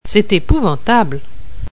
Accent d'insistance
Assurant la mise en relief d'une unité